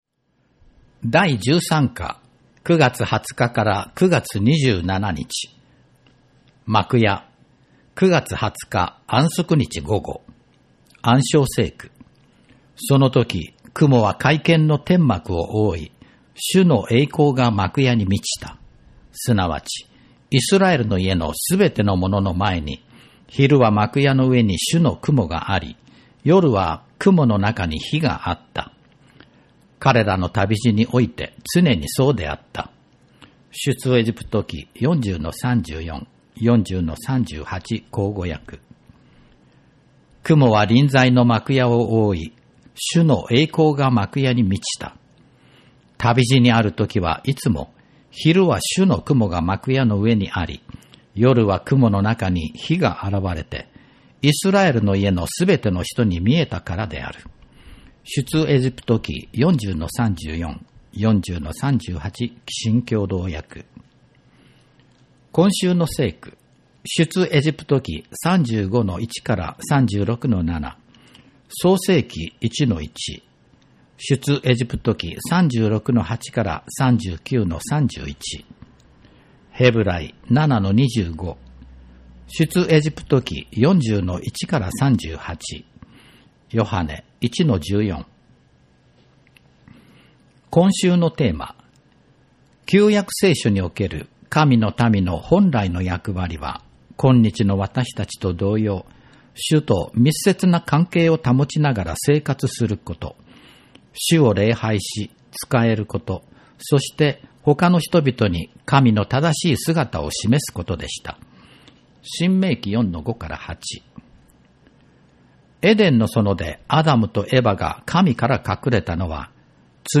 聖書研究ガイド朗読音源・2025年第3期 – 安息日学校部